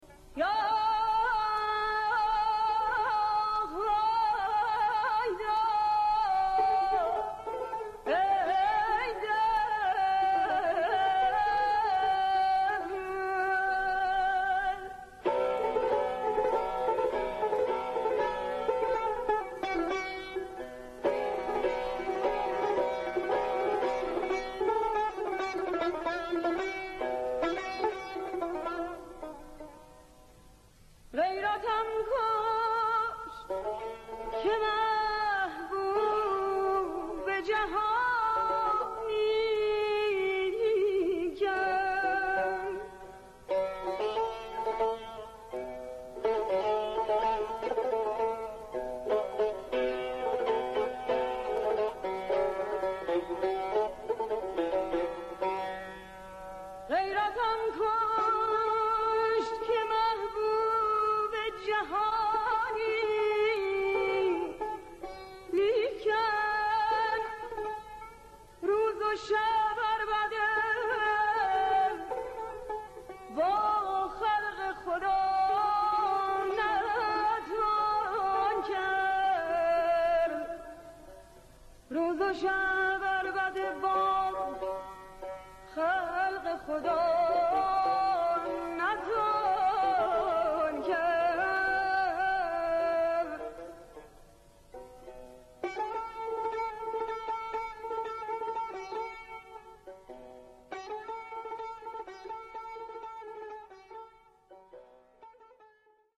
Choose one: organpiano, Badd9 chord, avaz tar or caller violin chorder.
avaz tar